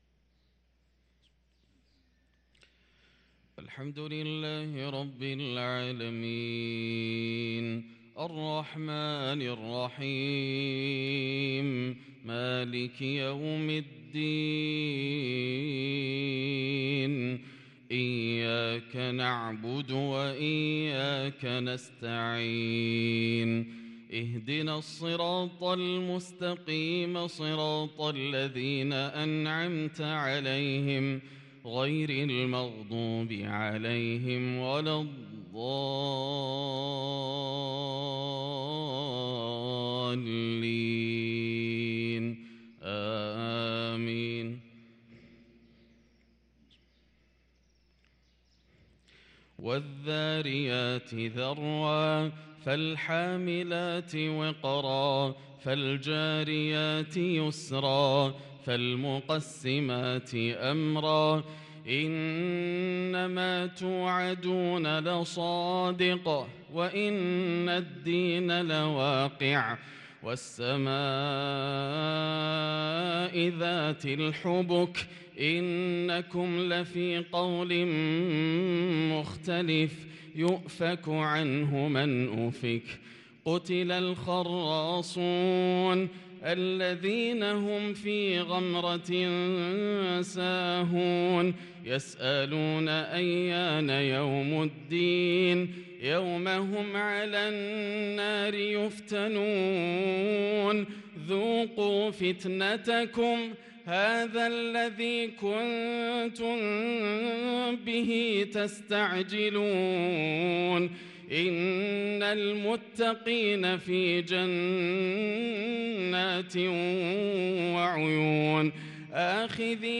صلاة الفجر للقارئ ياسر الدوسري 5 جمادي الآخر 1444 هـ
تِلَاوَات الْحَرَمَيْن .